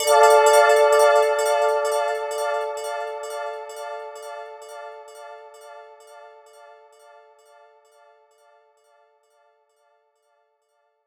Echoes_D_02.wav